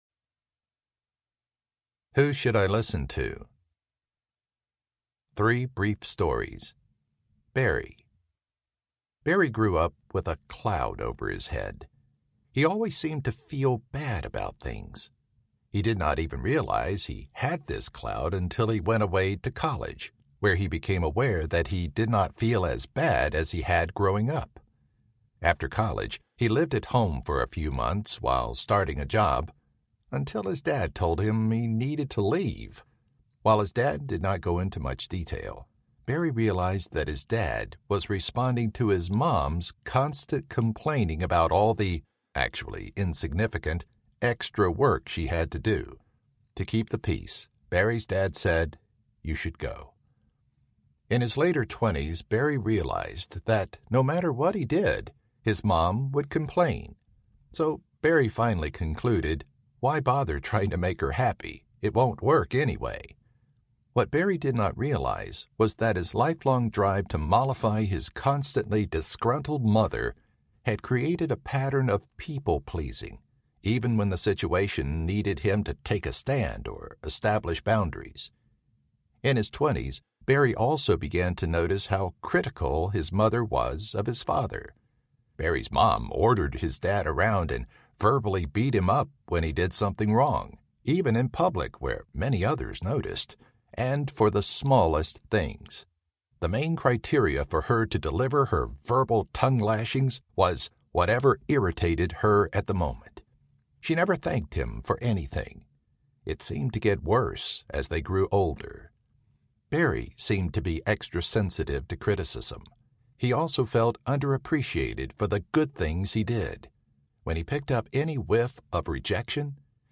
The First Will Be Last Audiobook
Narrator